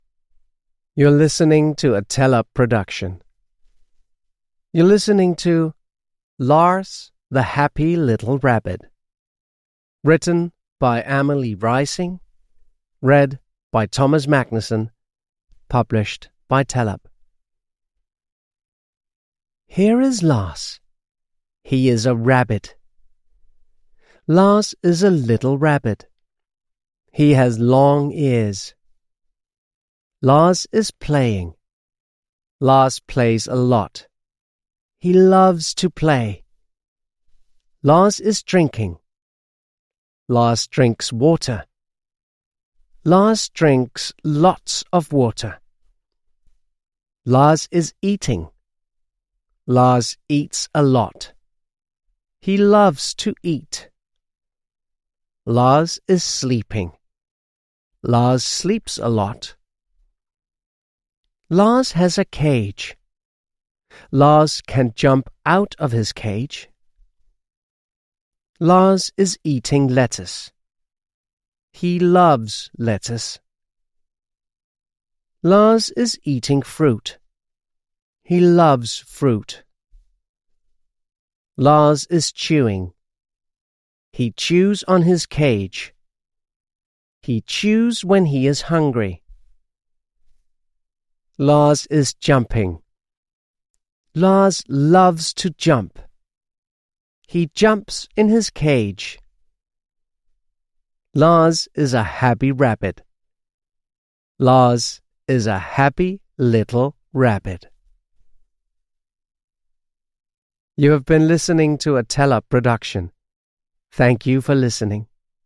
Reading Lars the Happy, Little Rabbit